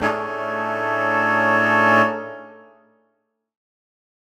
UC_HornSwell_Bmaj7b5.wav